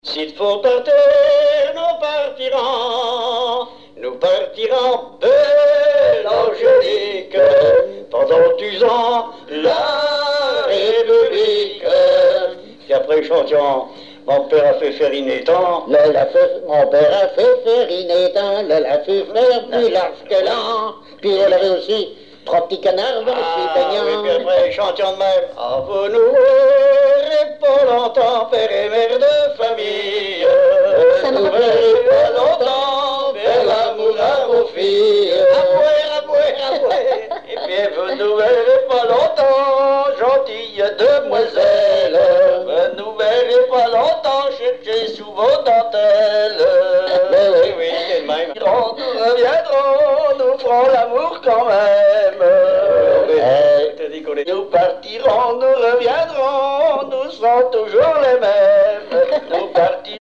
gestuel : à marcher
chanson locales et traditionnelles
Pièce musicale inédite